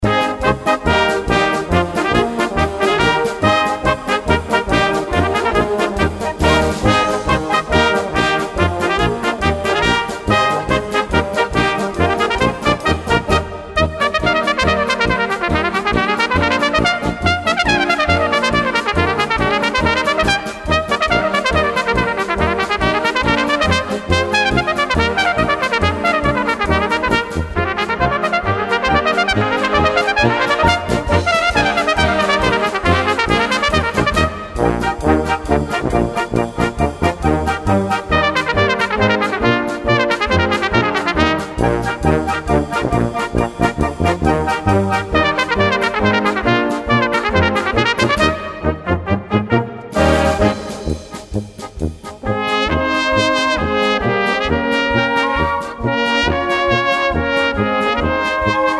Gattung: für Blasorchester
Besetzung: Blasorchester